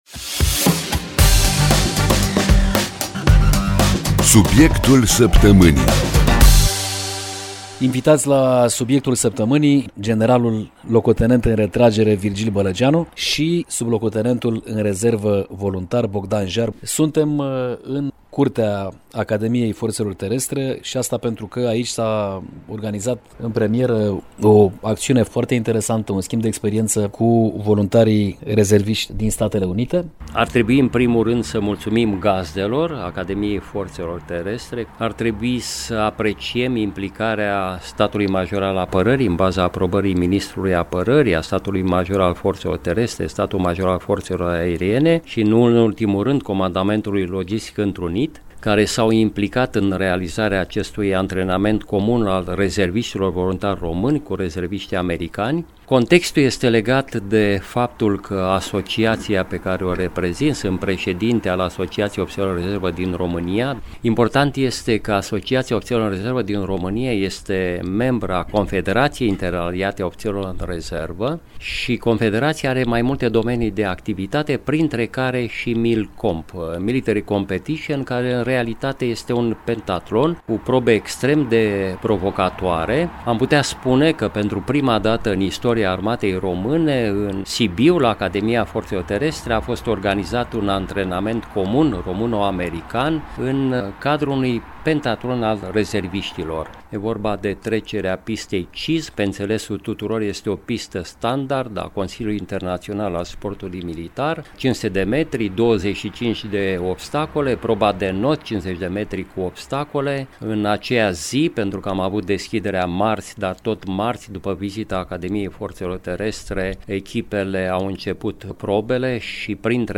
Este o primă misiune la scară mare care a fost dedicată rezerviștilor voluntari din România, și aici o să aflați din subiectul săptămânii, din interviul pe care l-am realizat seara târziu, chiar în curtea Academiei Forțelor Terestre, despre formatul MILCOMP, o competiție militară organizată anual de Confederația Interaliată a Ofițerilor în Rezervă, cea mai mare organizație a ofițerilor în rezervă din lume.